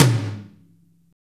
Index of /90_sSampleCDs/Roland - Rhythm Section/KIT_Drum Kits 5/KIT_Big Funk Kit